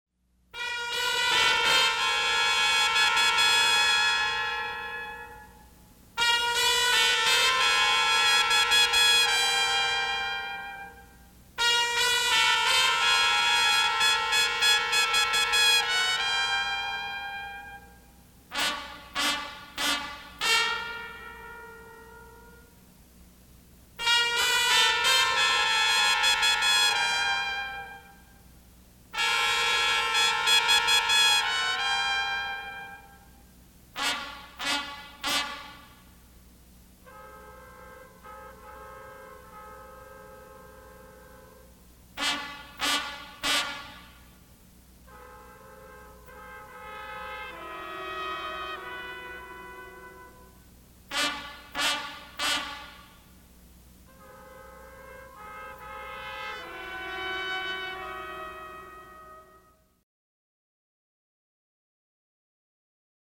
for four trumpets